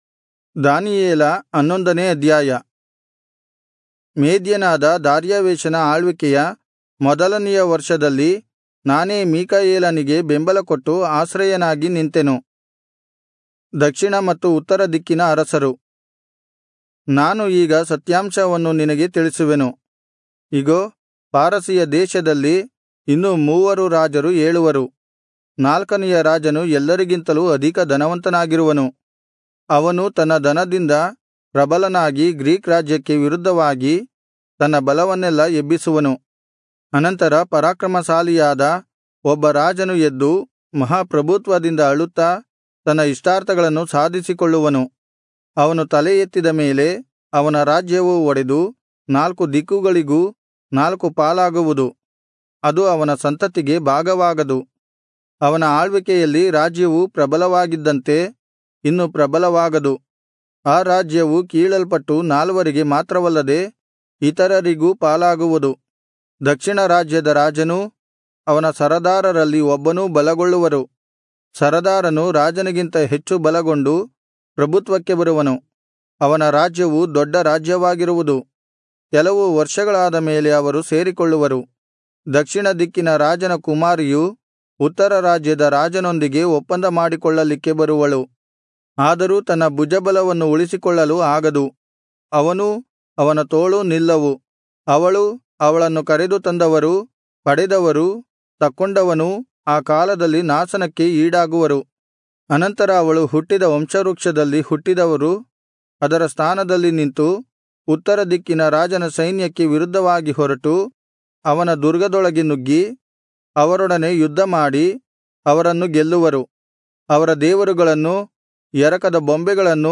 Kannada Audio Bible - Daniel 3 in Irvkn bible version